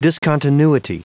Prononciation du mot discontinuity en anglais (fichier audio)
Prononciation du mot : discontinuity